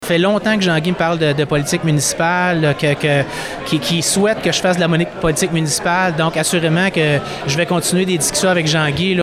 En conférence de presse lundi au Club de golf Godefroy, devant près d’une cinquantaine de personnes, il a souligné qu’il ne critiquait pas la gestion de la mairesse Lucie Allard, mais qu’il voulait amener sa propre vision du développement de la ville.